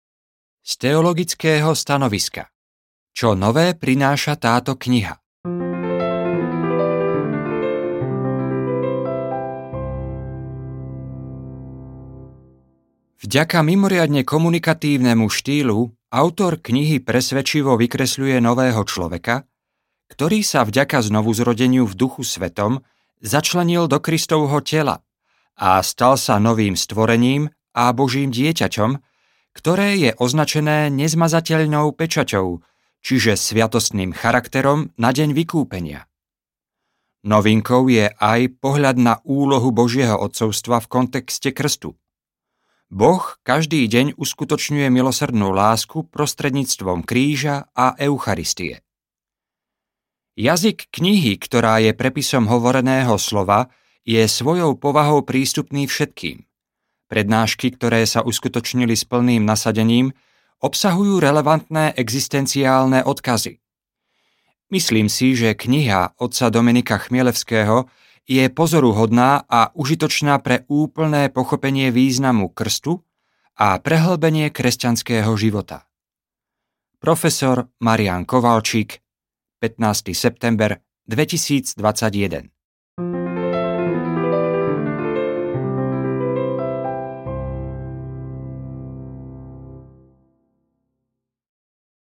Kristoterapia audiokniha
Ukázka z knihy